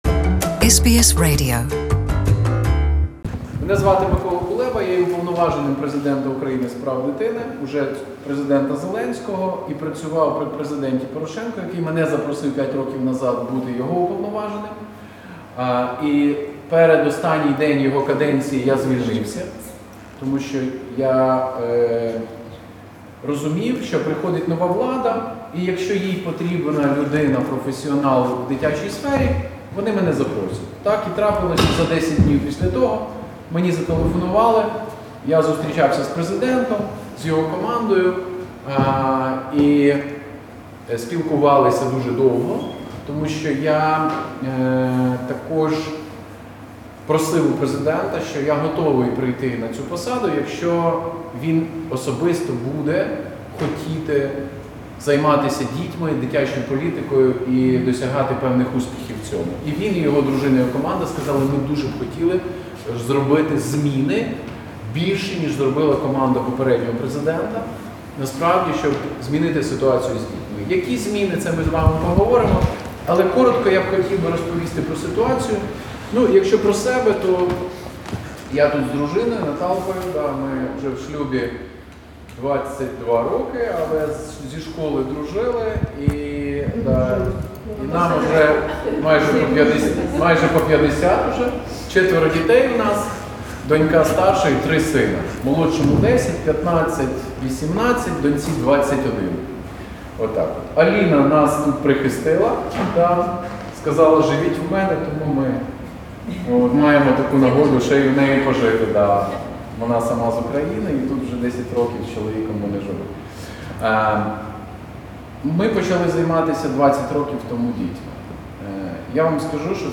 Meeting with Ukrainian community in Victoria. Essendon, Melbourne.